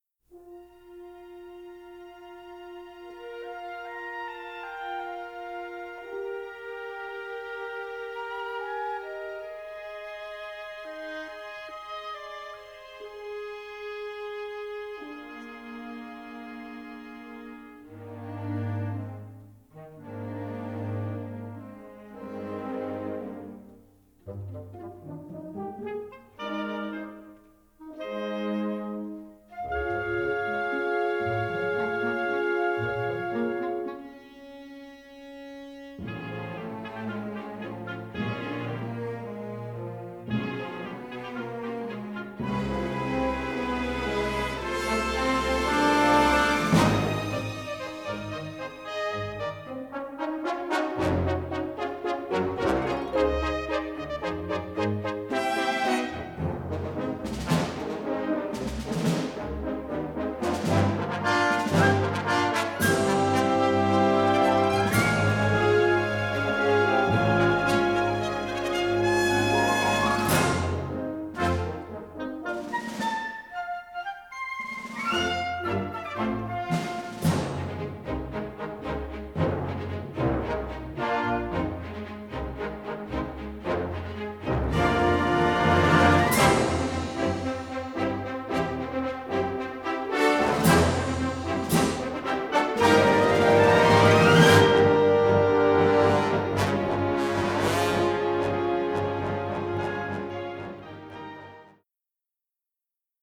Unusually crisp, punchy recording